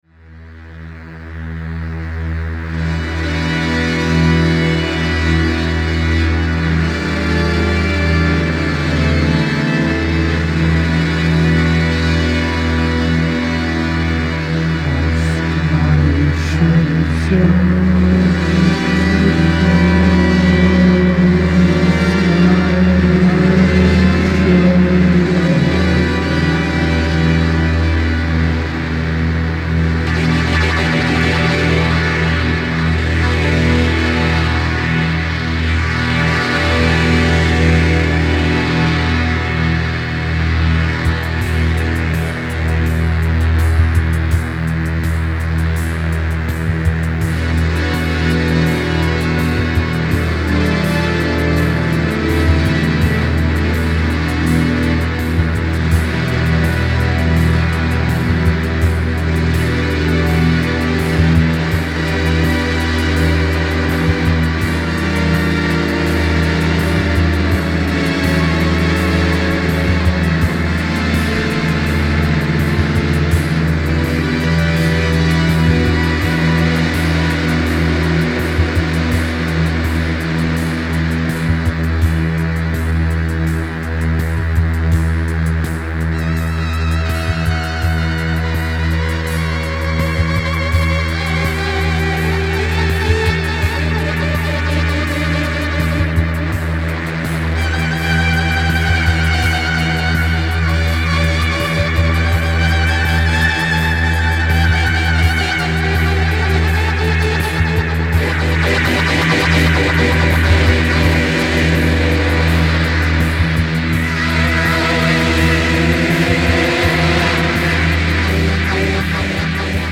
キーワード：ミニマル　即興　地球外　コズミック　サイケ